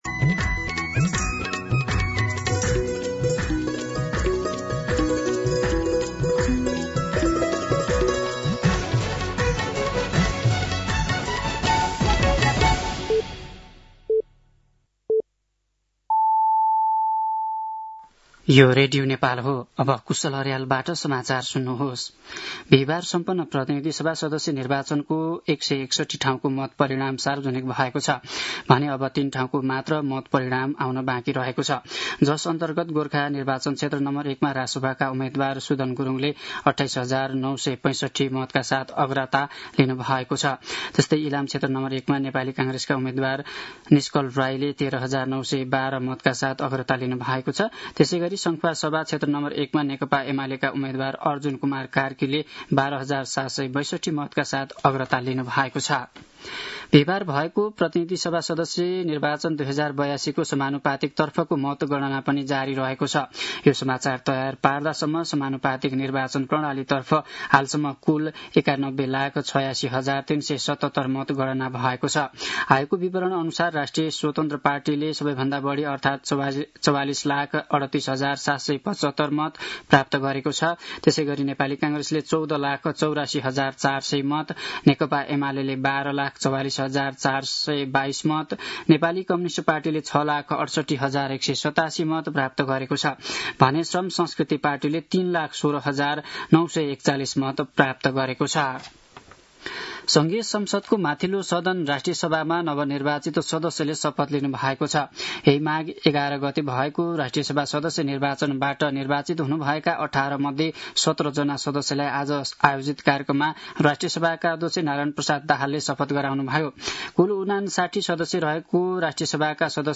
दिउँसो ४ बजेको नेपाली समाचार : २५ फागुन , २०८२
4-pm-Nepali-News.mp3